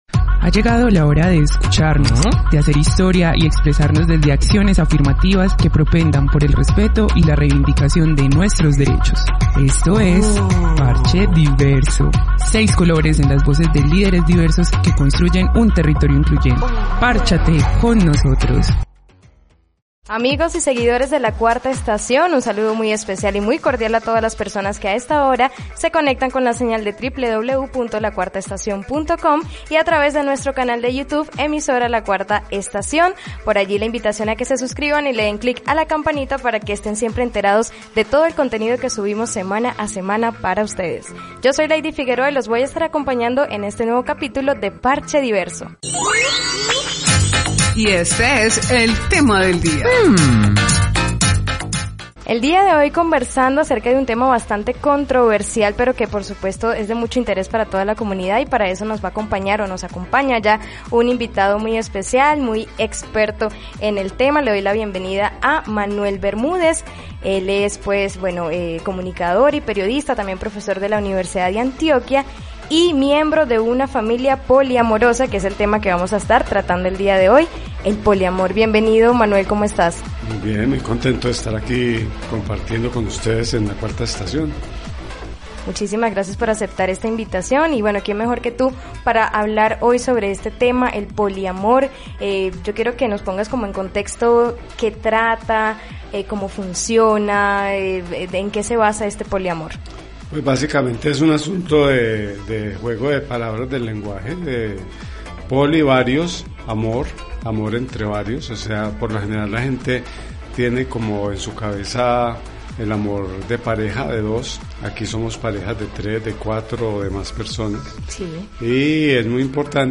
Escucha la entrevista completa en nuestro canal de YouTube y cuéntanos ¿Estarías dispuesto a estar en una relación poliamorosa?